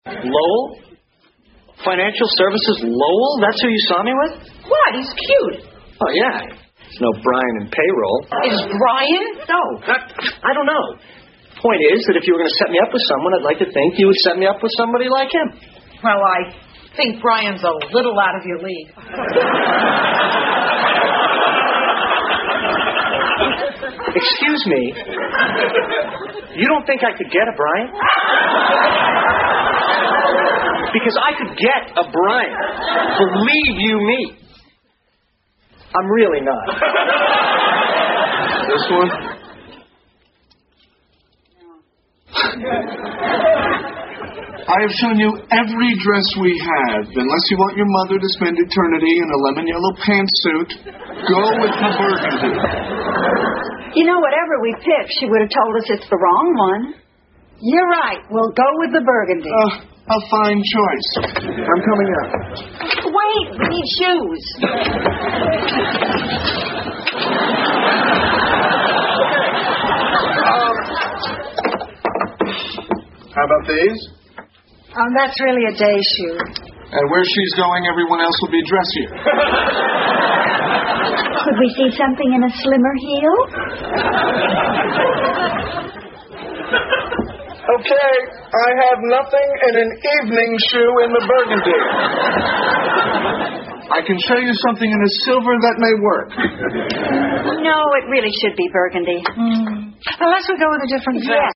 在线英语听力室老友记精校版第1季 第93期:祖母死了两回(7)的听力文件下载, 《老友记精校版》是美国乃至全世界最受欢迎的情景喜剧，一共拍摄了10季，以其幽默的对白和与现实生活的贴近吸引了无数的观众，精校版栏目搭配高音质音频与同步双语字幕，是练习提升英语听力水平，积累英语知识的好帮手。